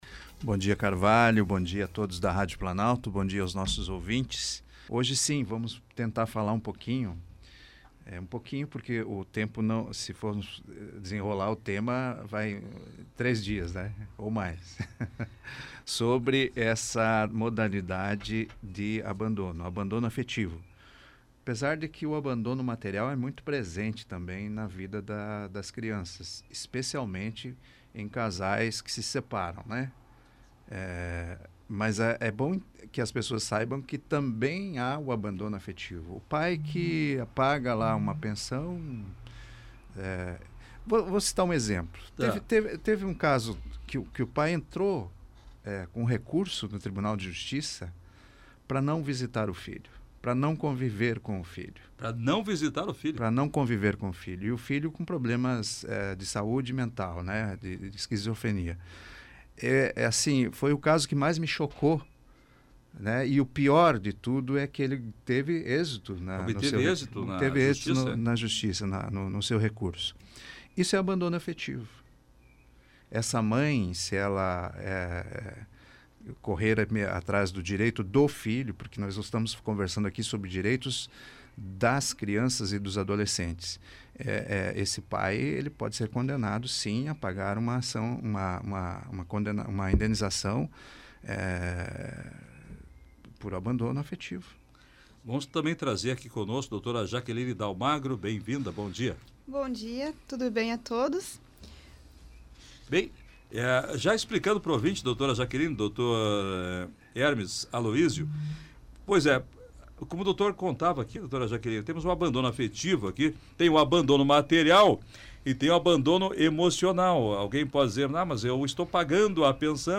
O abandono afetivo, uma preocupação na vida em sociedade, foi tema de entrevista na Rádio Planalto News (92.1).